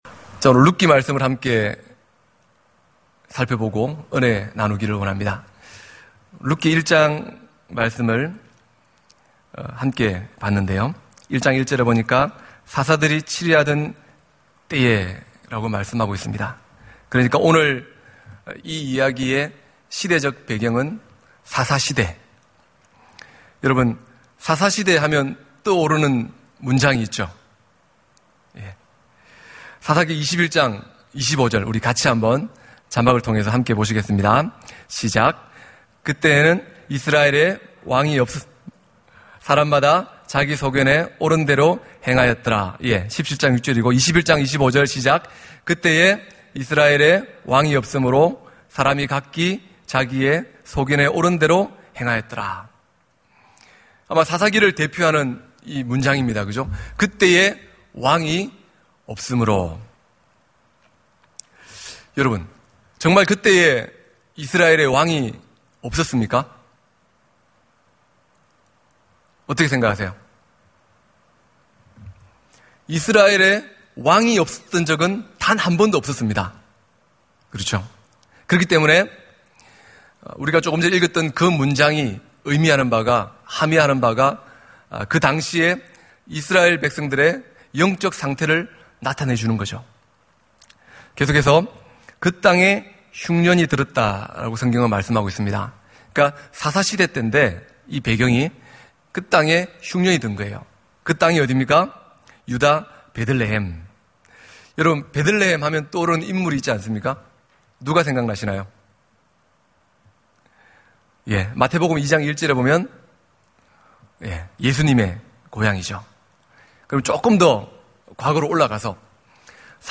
예배 수요예배